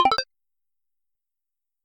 SFX_UI_Shop.mp3